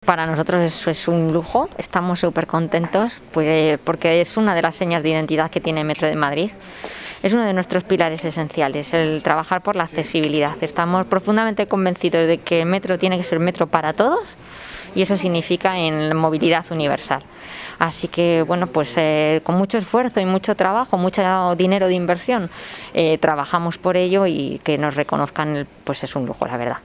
El reconocimiento a la fuerza y la solidaridad de la sociedad a través de la concesión de los Premios Solidarios ONCE 2021Abre Web externa en ventana nueva llegó, el pasado 15 de diciembre, a la Comunidad de Madrid, con la celebración de su ceremonia de entrega de galardones, que tuvo lugar en el Complejo Deportivo y Cultura de la ONCE, sito en el Paseo de La Habana, nº 208, de Madrid.